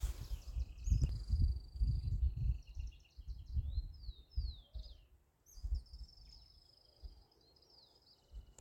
Straneck´s Tyrannulet (Serpophaga griseicapilla)
Life Stage: Adult
Location or protected area: Reserva Provincial Parque Luro
Condition: Wild
Certainty: Recorded vocal